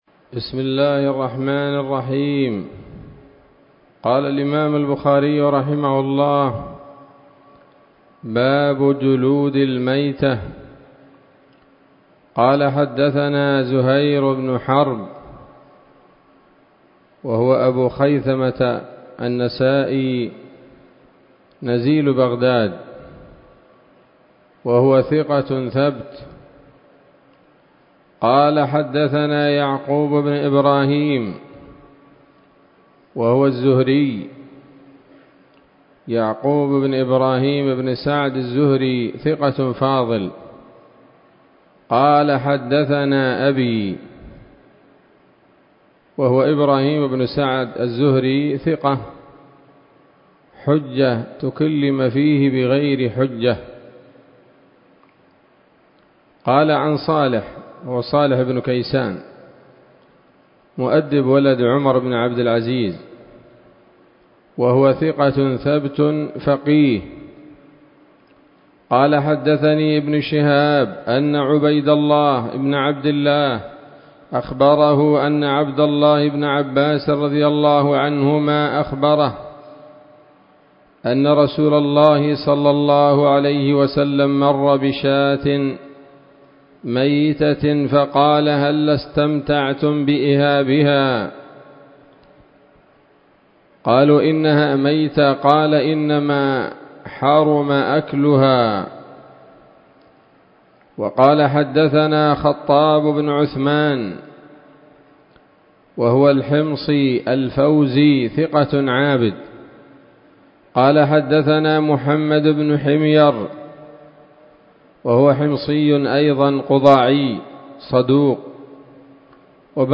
الدرس السادس والعشرون من كتاب الذبائح والصيد من صحيح الإمام البخاري